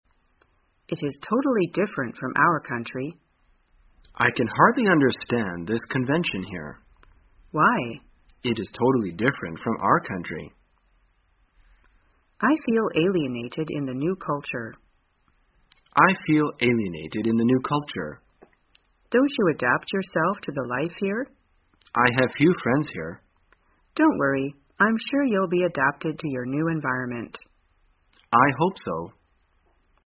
在线英语听力室生活口语天天说 第328期:怎样谈论不适应的听力文件下载,《生活口语天天说》栏目将日常生活中最常用到的口语句型进行收集和重点讲解。真人发音配字幕帮助英语爱好者们练习听力并进行口语跟读。